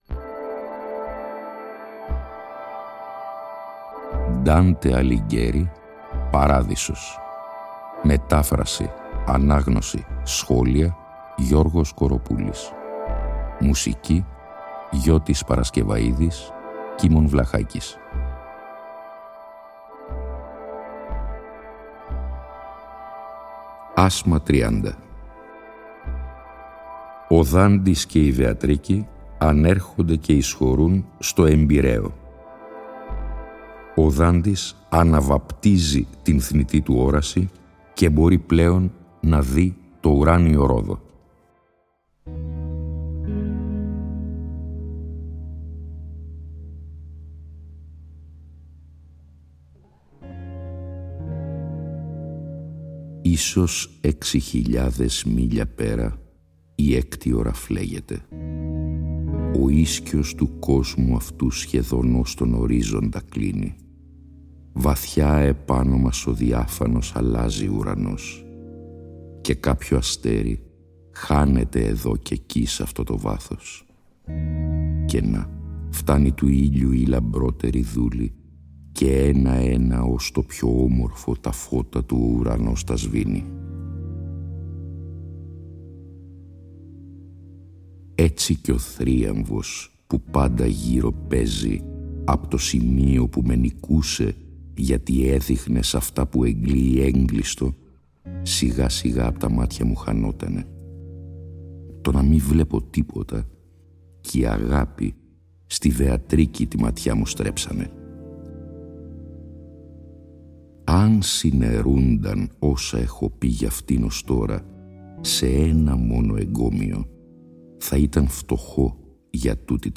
Η μετάφραση τηρεί τον ενδεκασύλλαβο στίχο και υποτυπωδώς την terza rima του πρωτοτύπου – στο «περιεχόμενο» του οποίου παραμένει απολύτως πιστή.